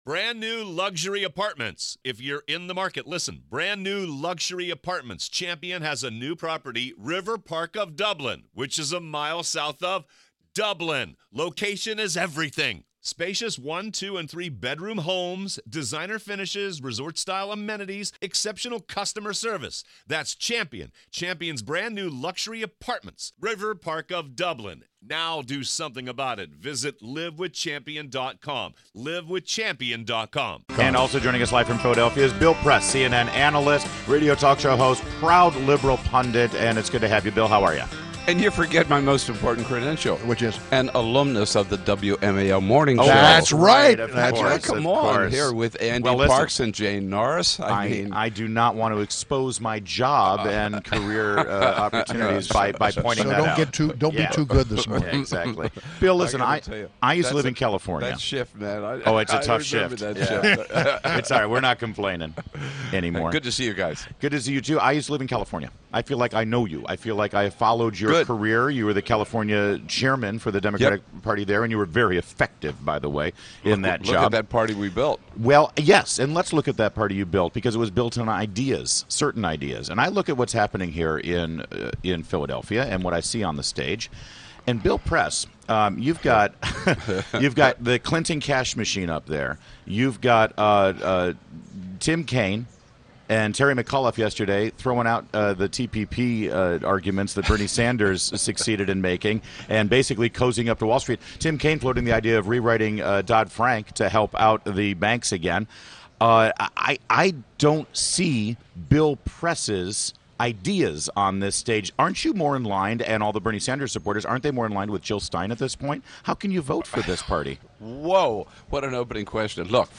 WMAL Interview - Bill Press - 07.28.16